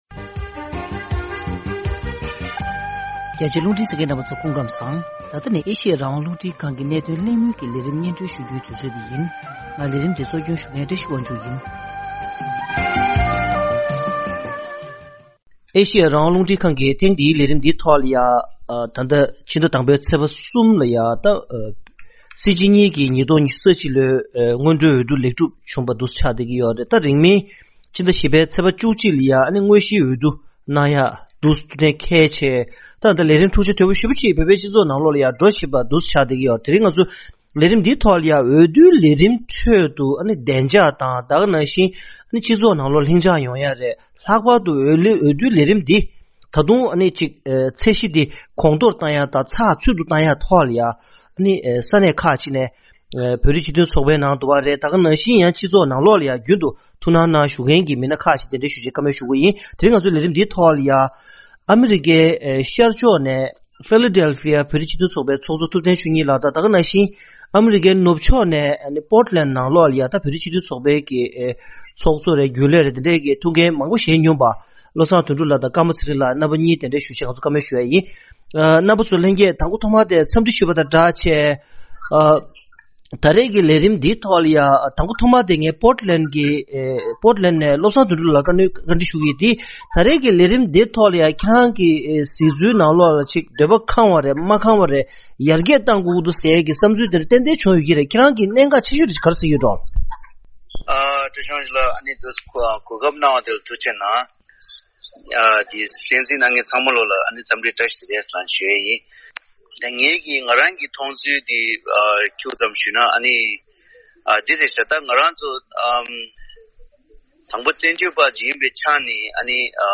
༄༅།།ཐེངས་འདིའི་གནད་དོན་གླེང་མོལ་གྱི་ལས་རིམ་ནང་།